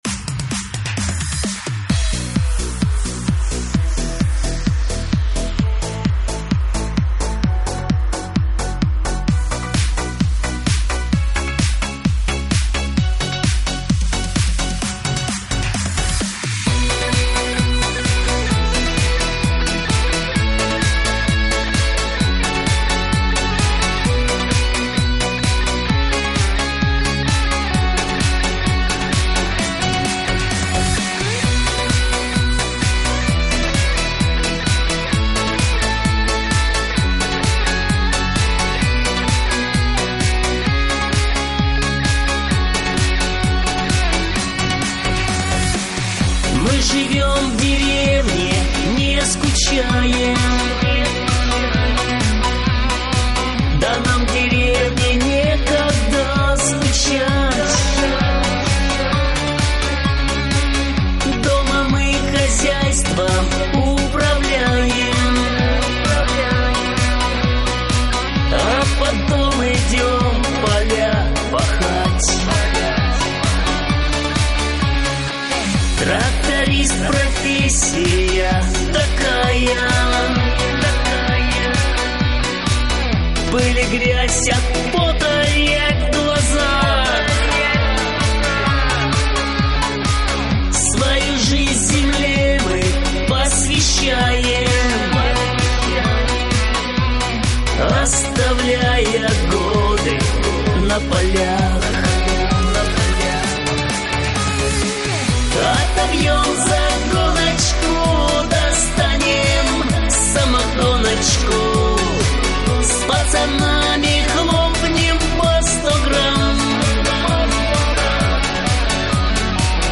народная-хороводная..